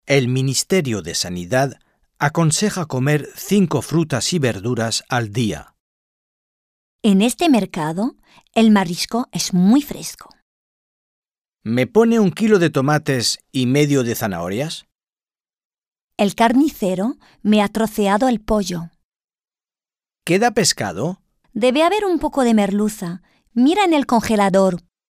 Un peu de conversation - Les fruits, les légumes, la viande, le poisson, les coquillages